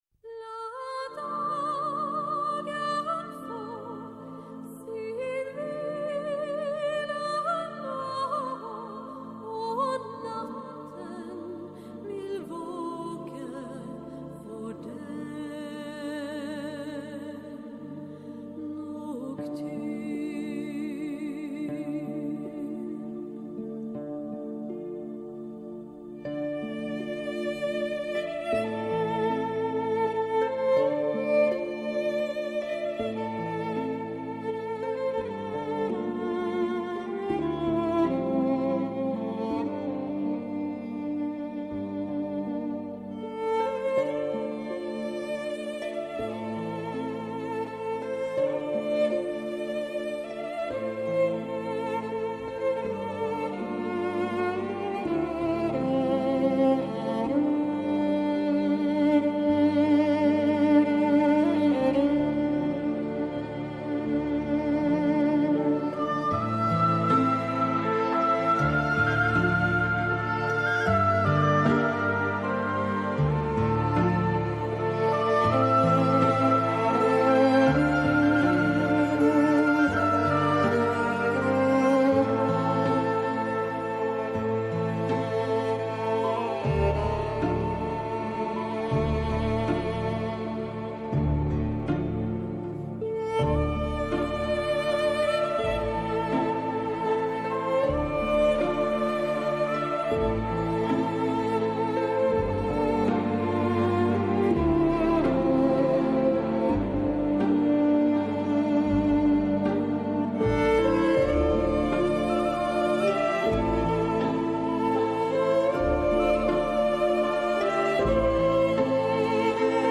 στο Πρώτο Πρόγραμμα της Ελληνικής Ραδιοφωνίας